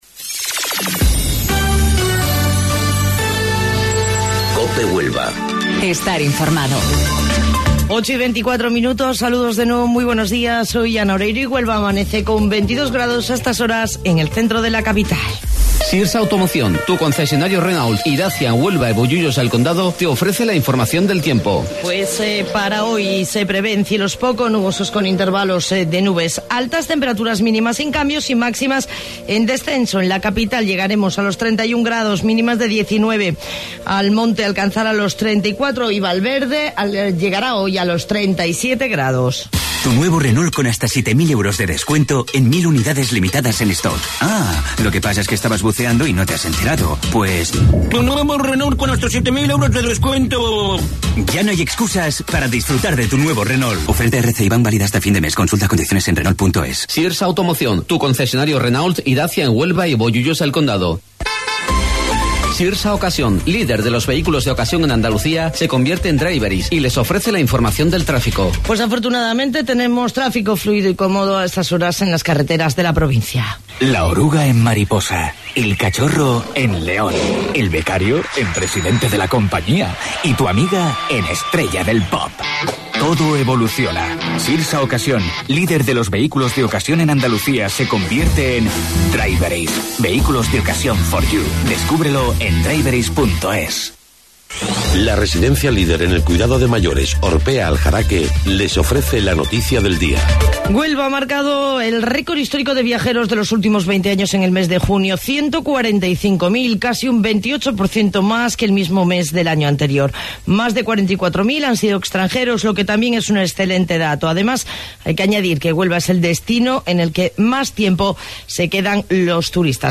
AUDIO: Informativo Local 08:25 del 25 de Julio